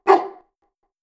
Wolf Bark.wav